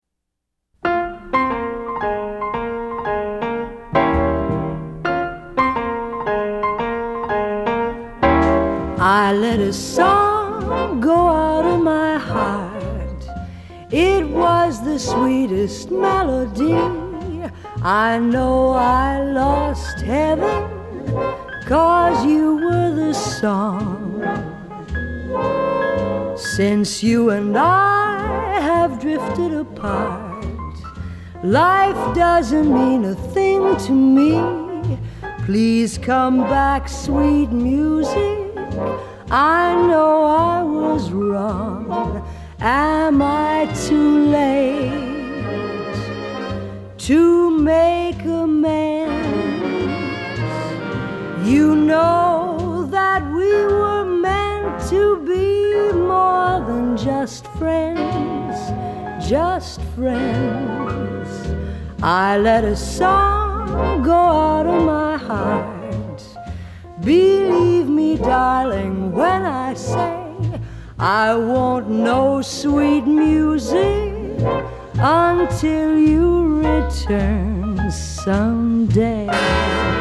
頂尖的樂團、一流的編曲，加上動人的演唱